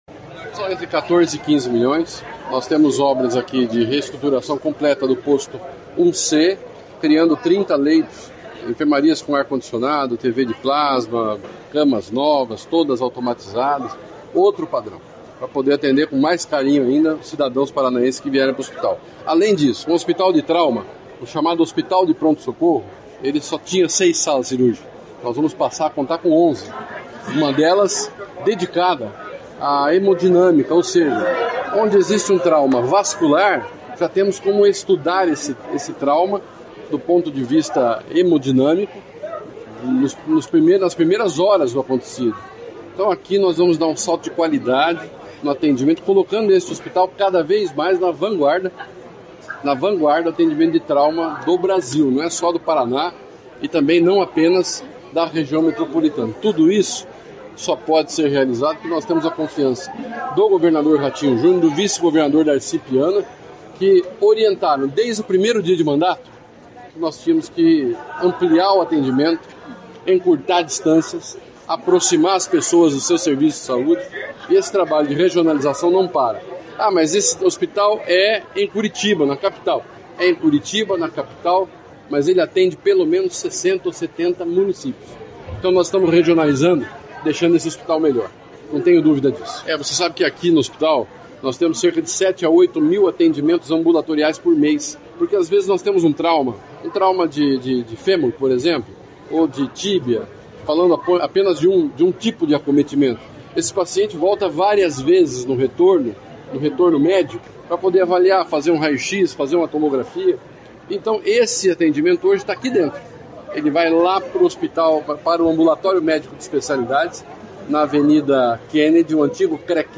Sonora do secretário da Saúde, Beto Preto, sobre o novo centro cirúrgico e enfermaria no Hospital do Trabalhador de Curitiba | Governo do Estado do Paraná